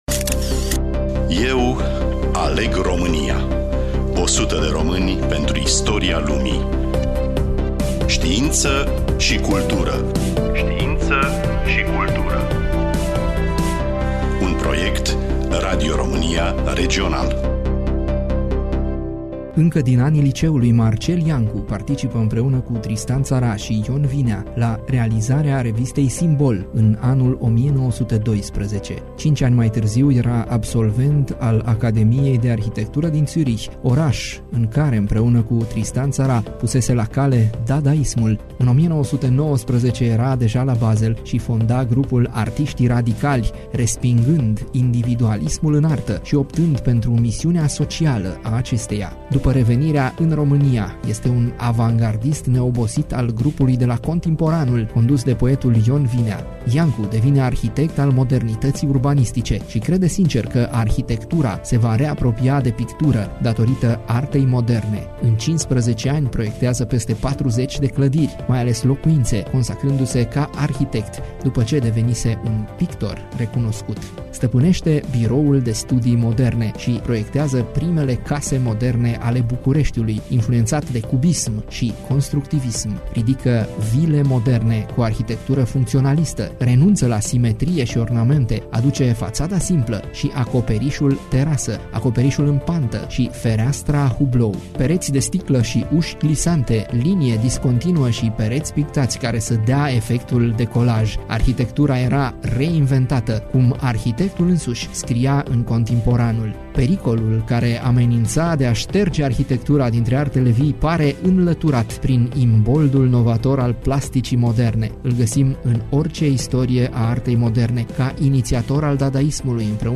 Voiceover